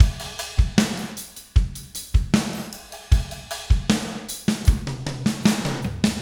Spaced Out Knoll Drums 06 Filll.wav